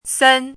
chinese-voice - 汉字语音库